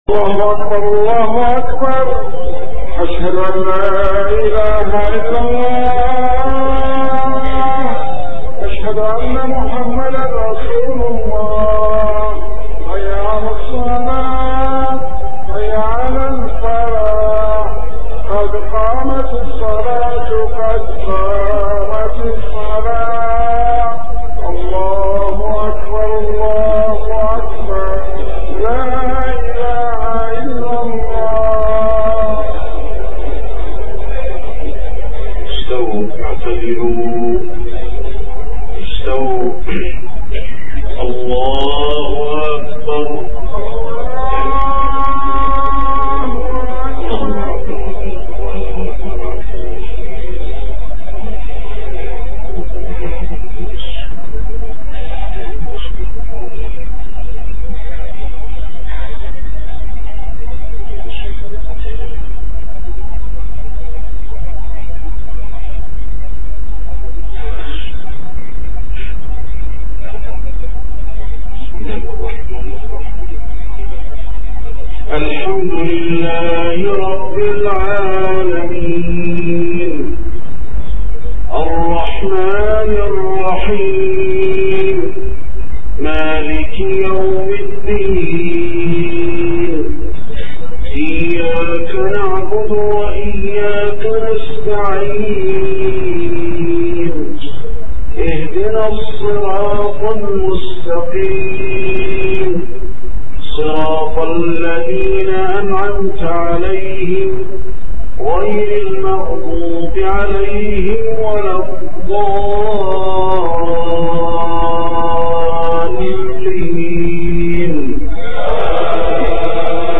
صلاة العشاء 2 محرم 1430هـ سورتي الشمس والعاديات > 1430 🕌 > الفروض - تلاوات الحرمين